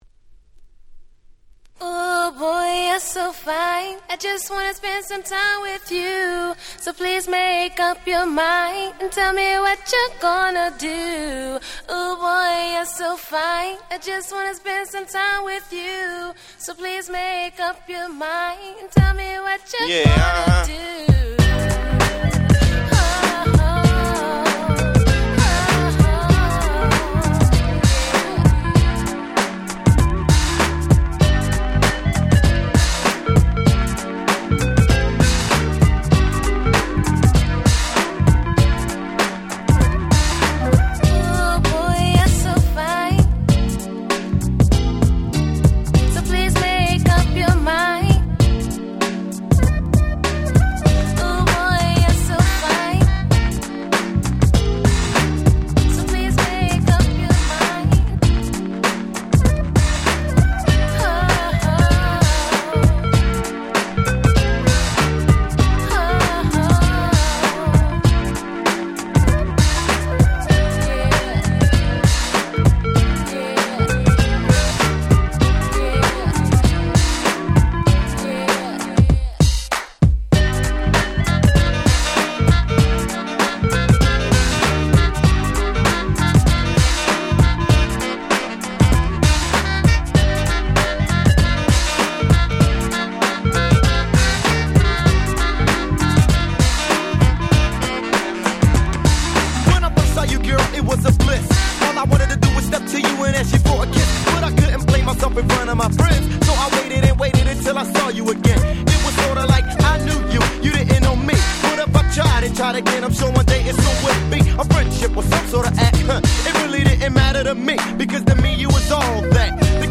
94' 超人気歌ラップ！！
キラキラキャッチーなBeatにサビでは哀愁の女性Vocalとまさに日本人受け抜群の超人気曲です！
こちらの日本盤再発にのみイントロをRe-EditしてDJ仕様にした「Extended Remix」を収録！！
シャダシャス キャッチー系 90's Boom Bap ブーンバップ